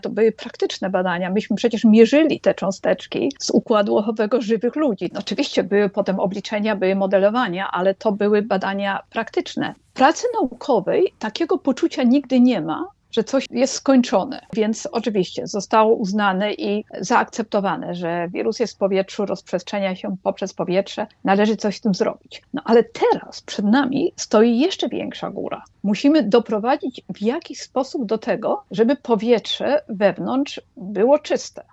Kilka miesięcy temu tak mówiła o swoich badaniach w reportażu przygotowanym przez dziennikarzy Polskiego Radia Rzeszów: